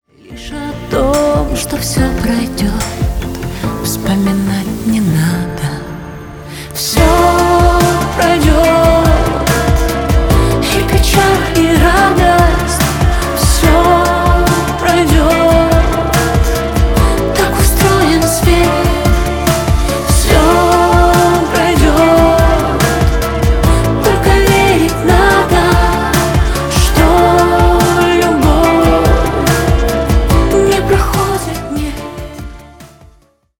Поп Музыка
кавер # грустные